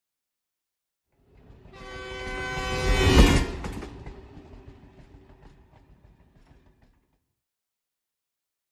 Train Whistle By European 3 - L to R